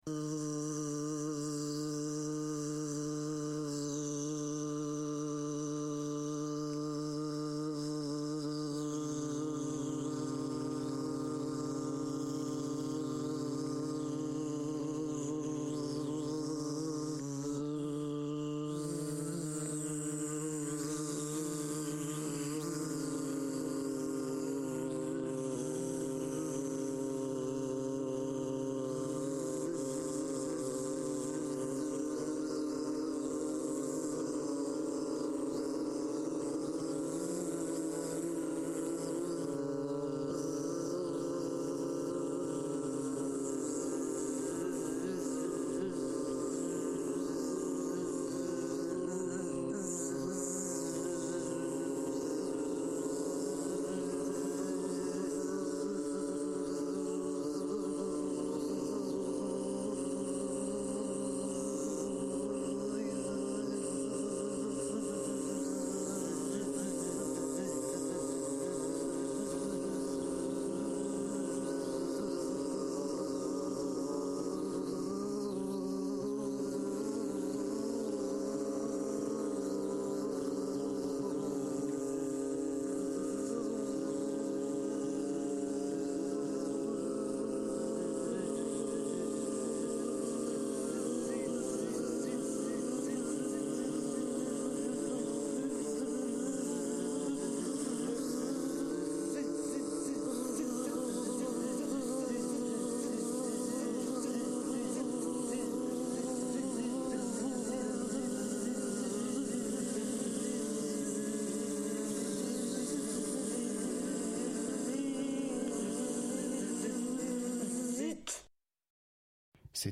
Une courbe concrète, bruitiste et électronique.
On y écoute des espèces et des espaces de son, des extraits d ́oeuvres et des petites formes avec leurs auteur(e)s. C’est encore une manière de mener une recherche intime sur le sonore, et ses possibles. Entrez dans un cinéma pour l’oreille.